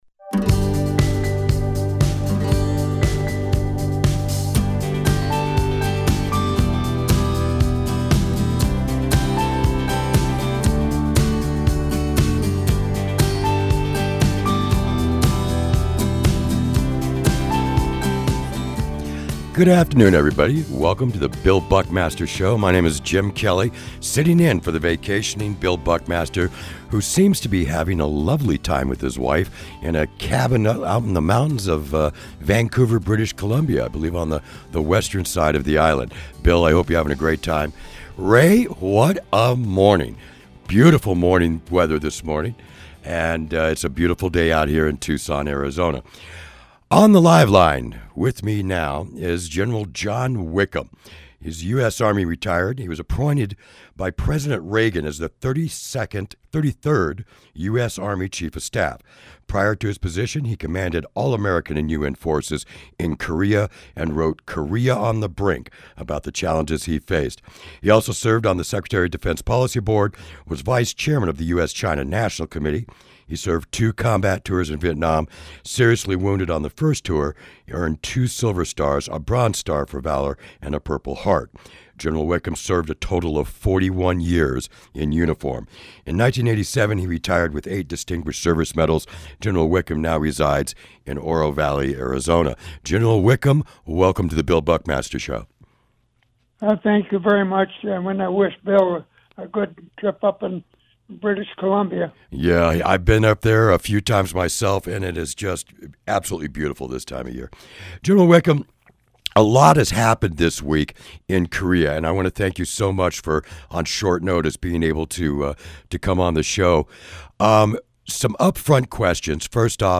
Followed by an interview